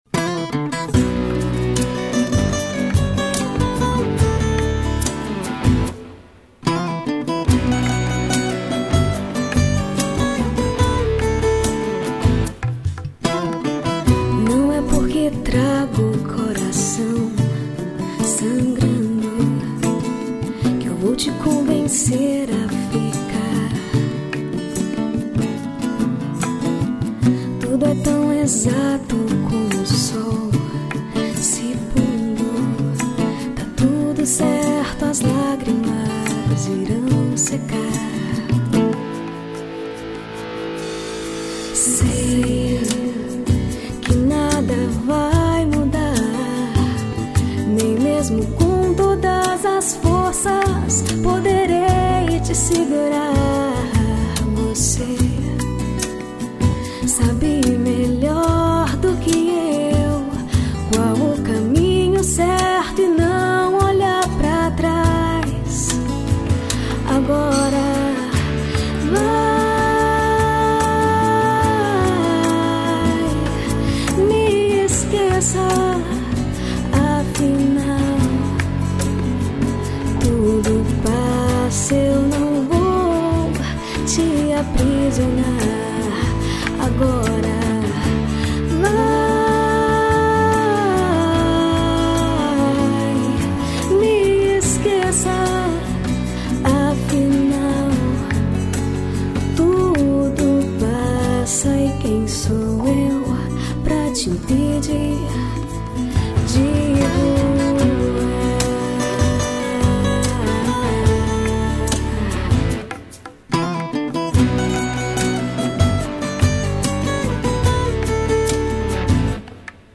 2811   04:09:00   Faixa: 1    Mpb